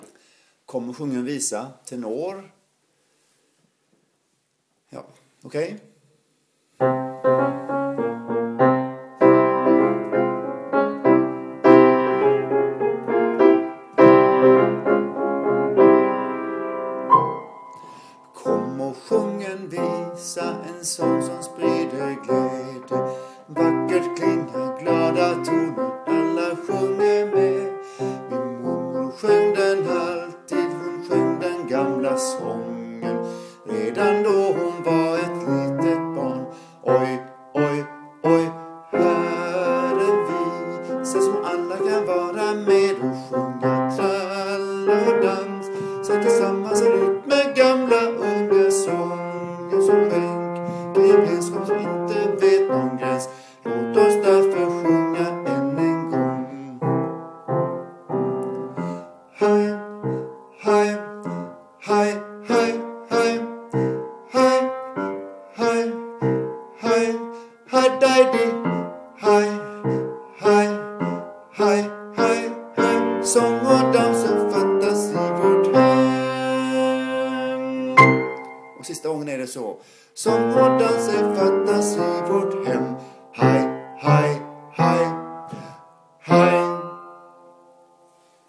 Kom och låt oss sjunga tenor
Komochlatosssjunga_tenor.mp3